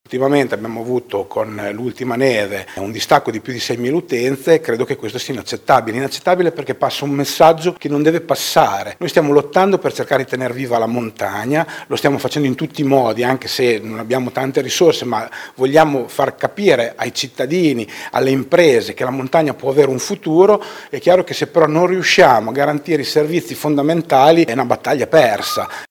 Il presidente della Provincia di Modena Fabio Braglia: